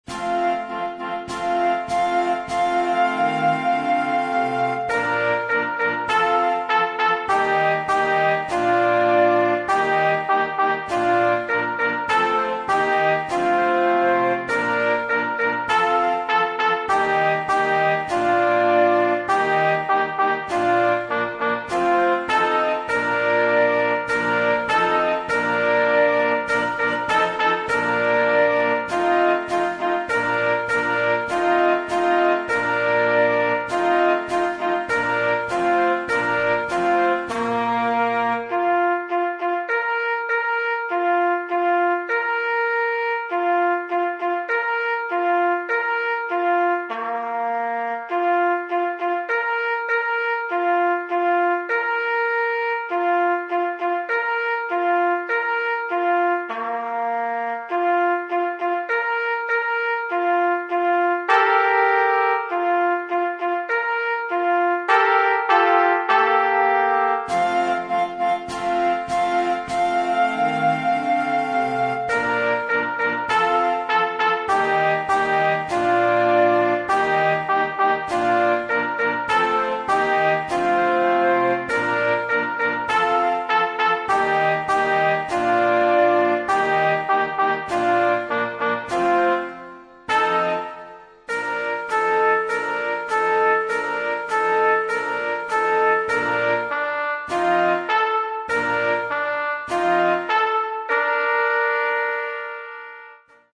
Partitions pour ensemble flexible, 4-voix + percussion.